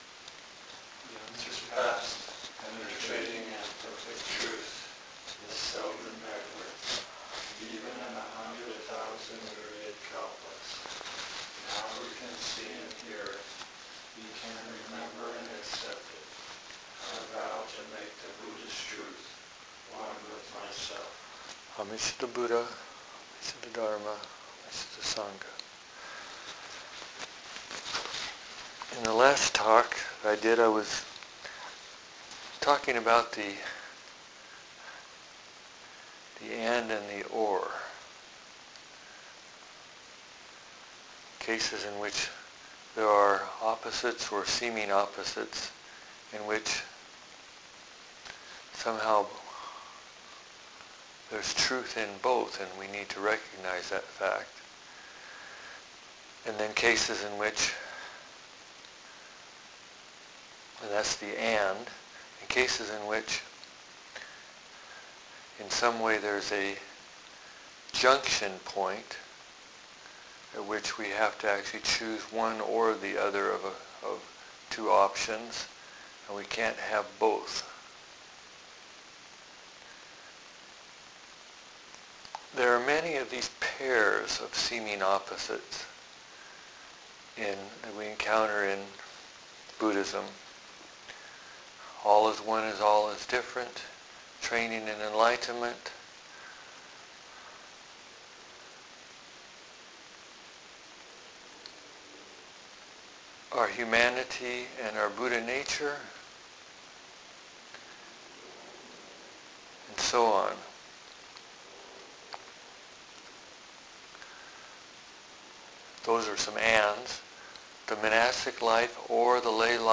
DHARMA TALKS —2018